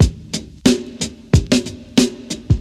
• 121 Bpm Breakbeat F# Key.wav
Free drum groove - kick tuned to the F# note. Loudest frequency: 1217Hz
121-bpm-breakbeat-f-sharp-key-ijB.wav